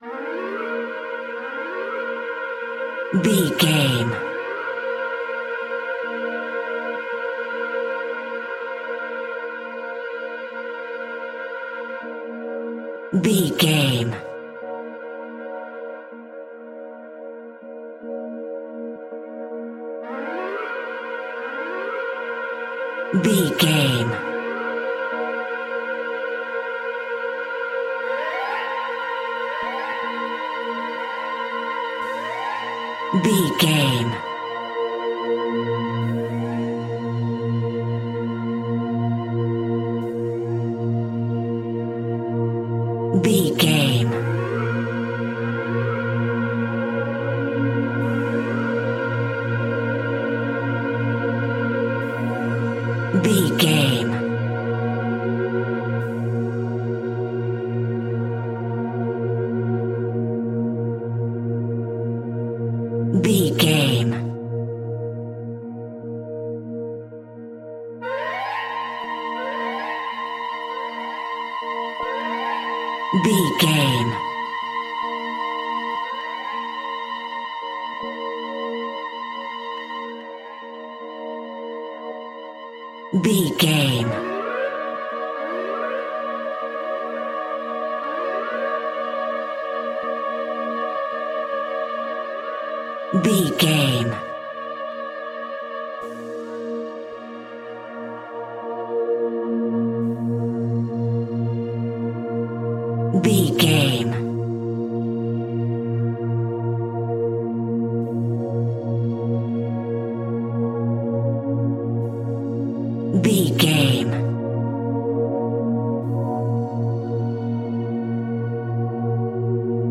Aeolian/Minor
B♭
Slow
scary
tension
ominous
dark
suspense
eerie
orchestra
flutes
strings
synthesiser
oboe
pads